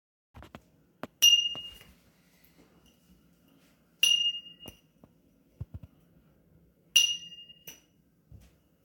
Coin flip.m4a
big coin Coin ding flip ping pling sound effect free sound royalty free Sound Effects